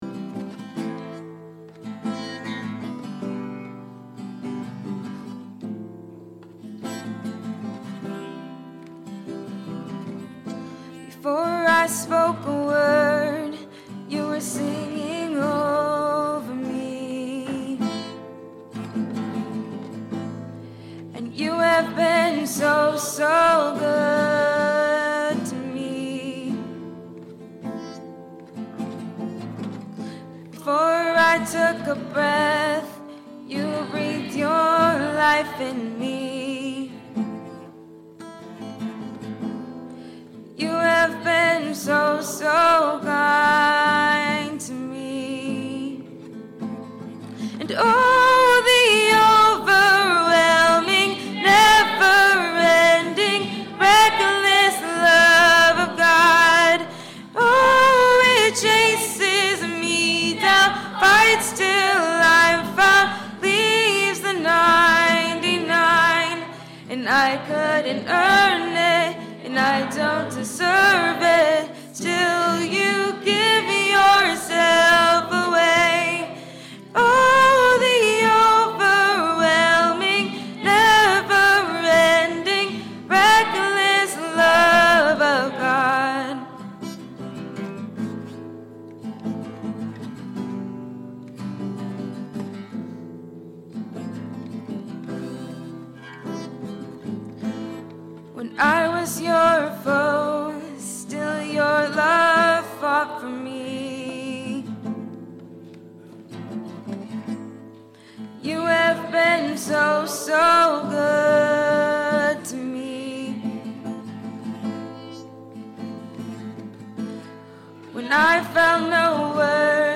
The Message Of Hope For An Outcast-A.M. Service